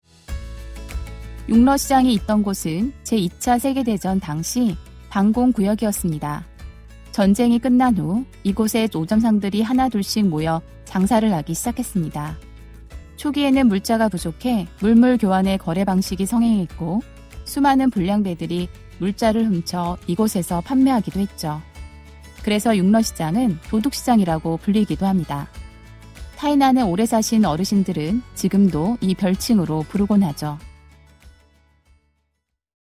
한국어 음성 안내